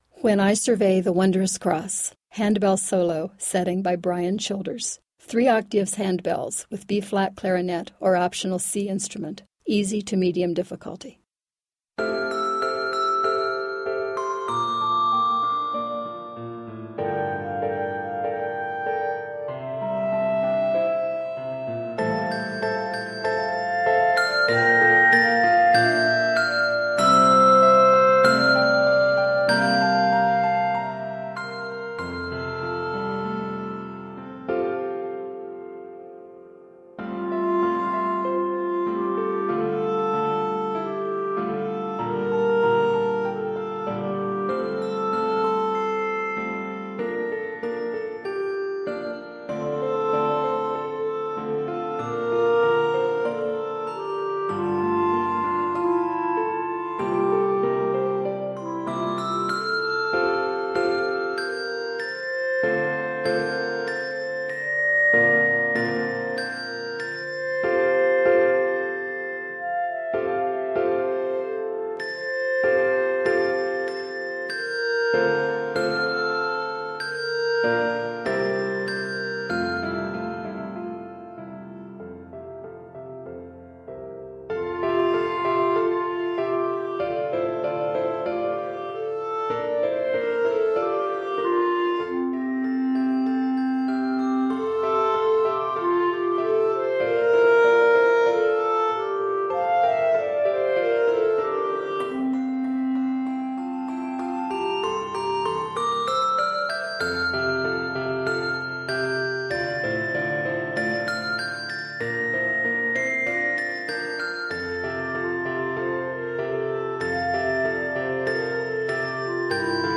Scored in F Major, it is 57 measures.
Duet
Hymn Tune